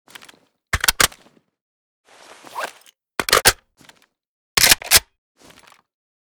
rpk_reload_empty.ogg